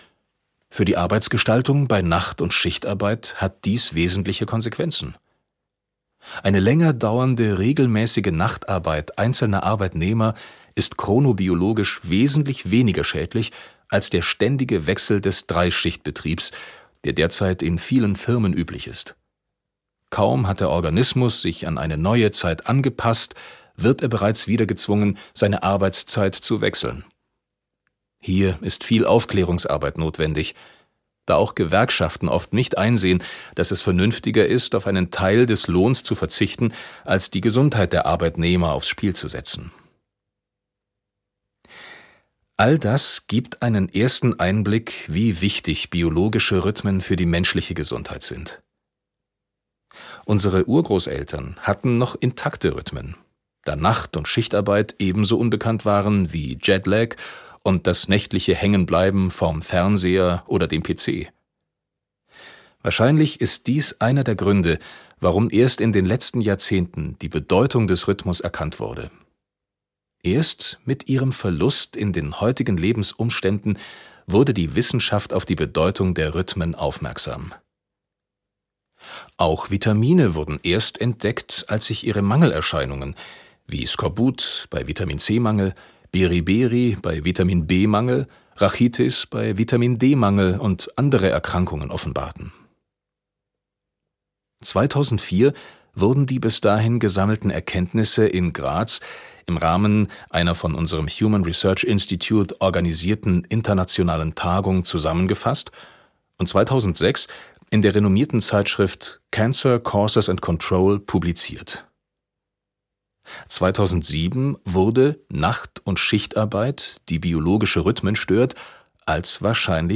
Vom richtigen Umgang mit der Zeit - Maximilian Moser - Hörbuch